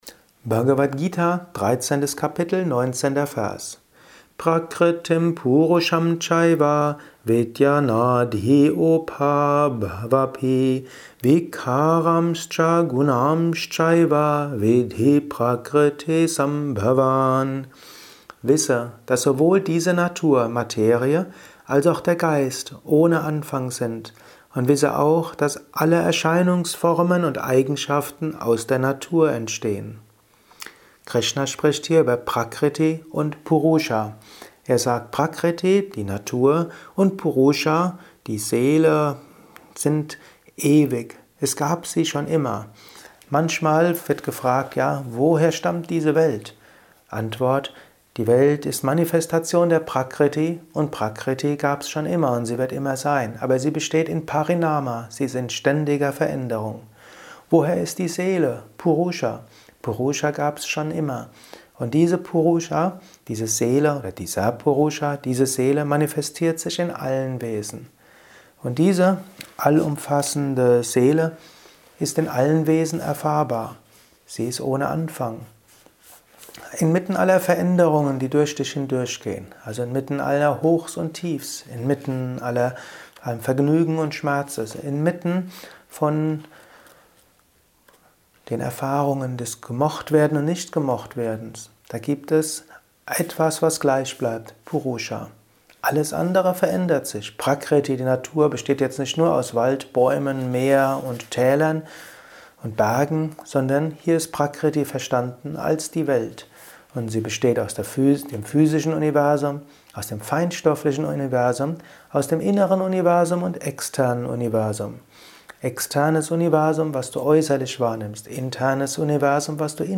Kurzvorträge
Dies ist ein kurzer Kommentar als Inspiration für den heutigen